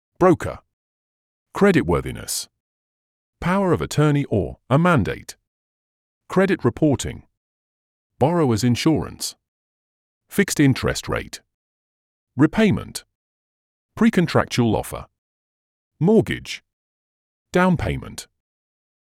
English vocabulary !
Entraînez-vous à prononcer ces mots en anglais. Cliquez sur les icônes fille et garçon pour écouter la prononciation.